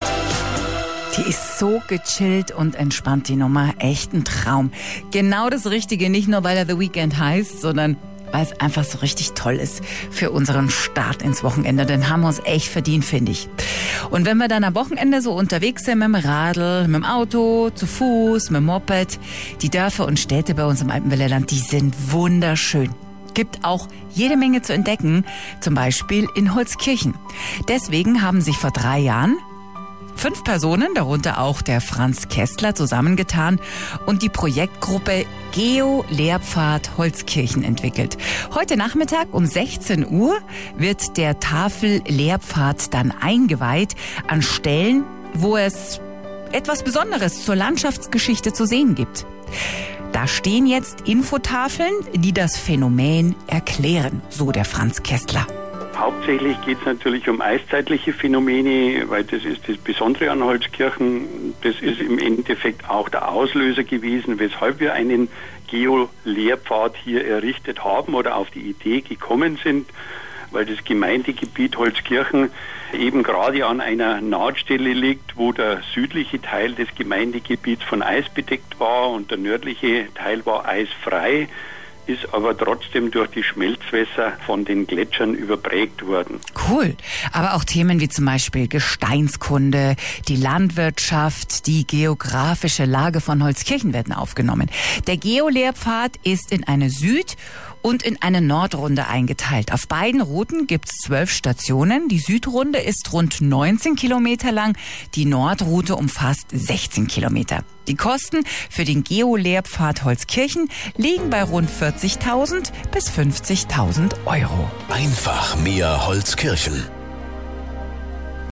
Zwei kurze Interviews, von eines am Vormittag und eines am Nachmittag des 11. Juni 2021 gesendet wurden